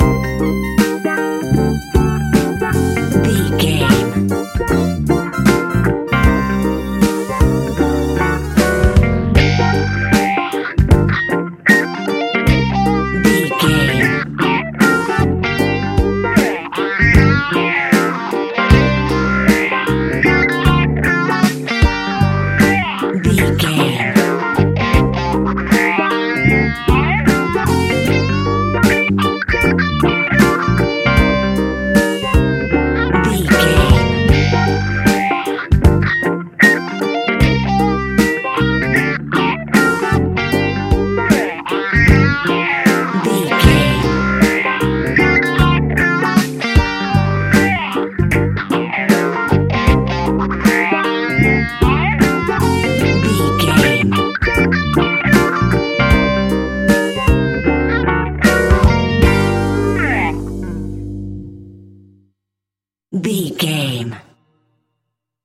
Epic / Action
Fast paced
In-crescendo
Uplifting
Ionian/Major